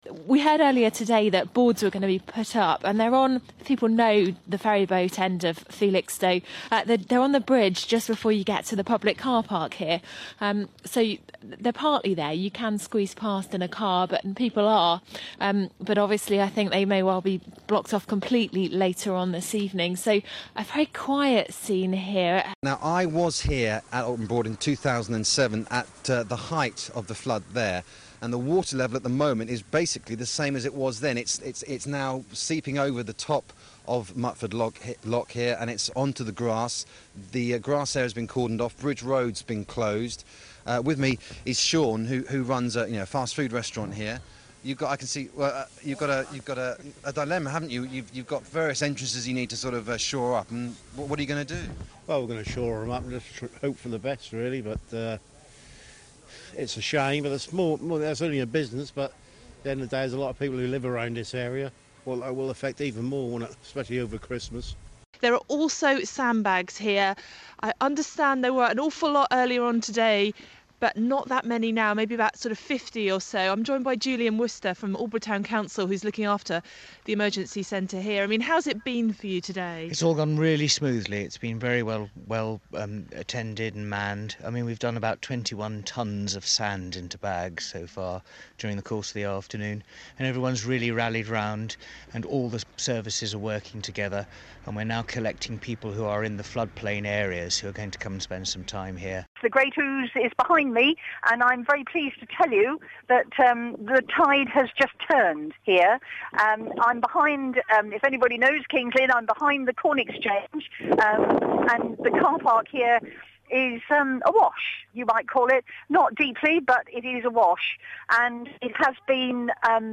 Reporters for BBC Radio Suffolk keeping a watching eye on the flood waters.